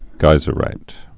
(gīzə-rīt)